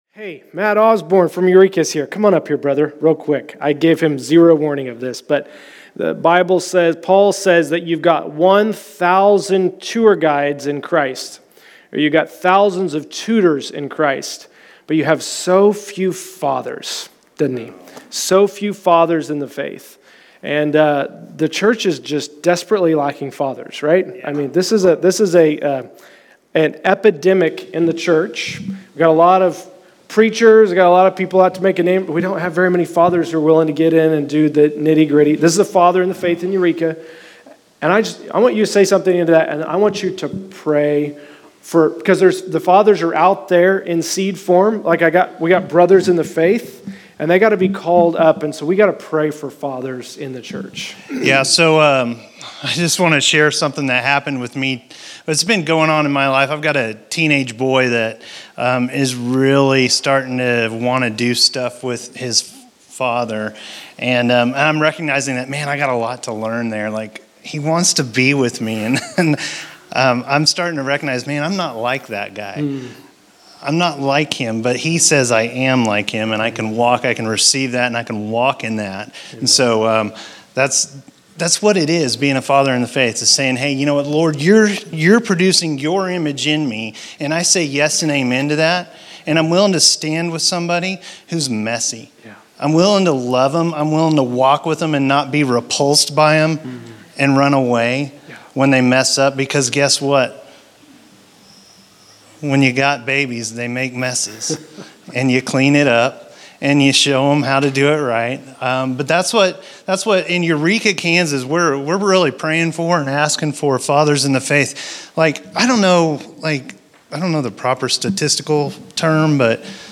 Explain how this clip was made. Location: El Dorado